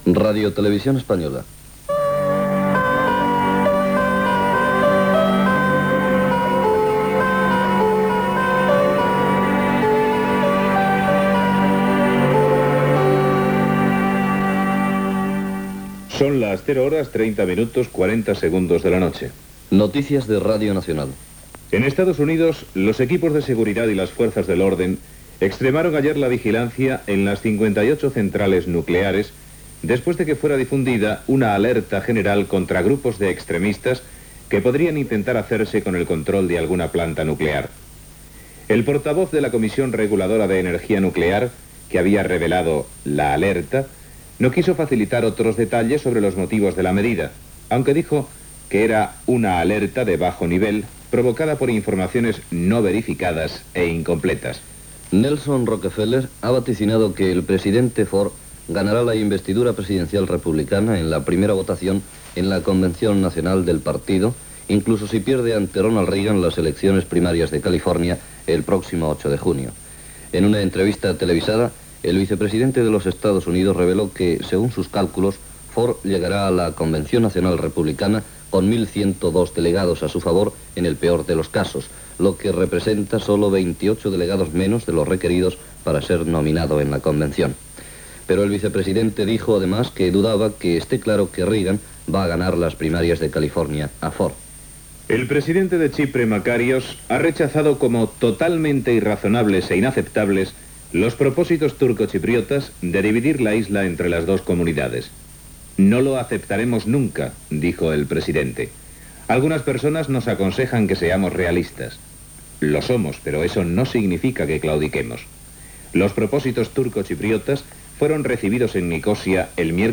Identificació i sintonia de l'emissora, hora, alerta a les centrals nuclears dels EE.UU. , canditats a les eleccions a la presidència dels EE.UU., visita dels reis d'Espanya a Santo Domingo, etc. Resultats dels partits de futbol masculí
Informatiu